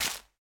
Minecraft Version Minecraft Version snapshot Latest Release | Latest Snapshot snapshot / assets / minecraft / sounds / block / big_dripleaf / break5.ogg Compare With Compare With Latest Release | Latest Snapshot
break5.ogg